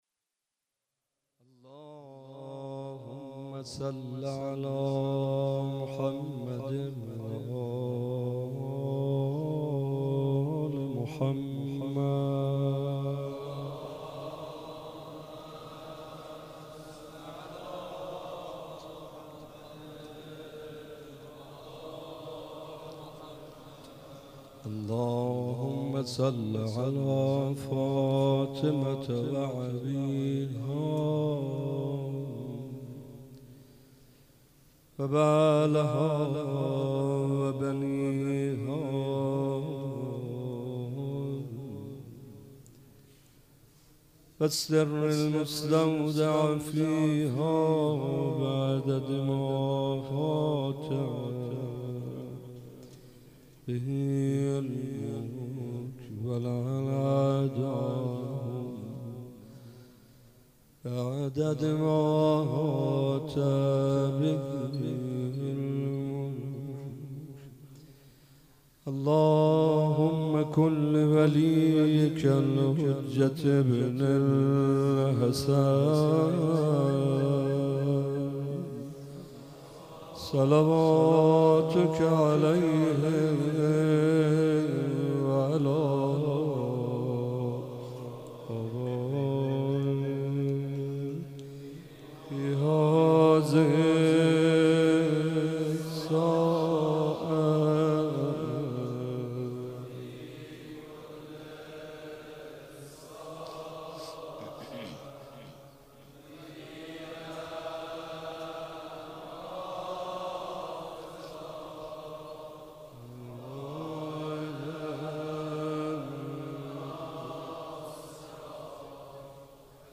مداح : محمدرضا طاهری قالب : روضه پیش زمینه مناجات